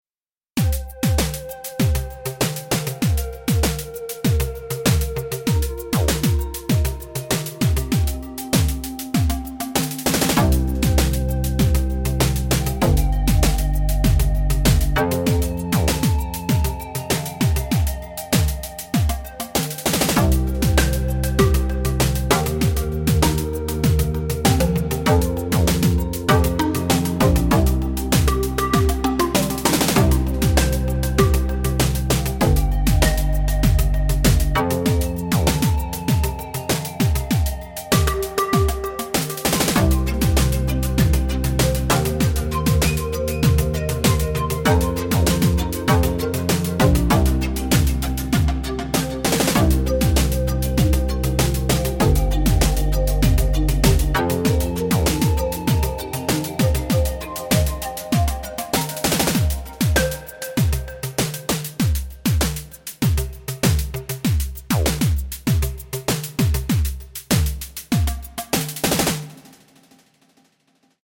A little poppy thing made only using FM drum
Love the acoustic/metallic percussions on this one.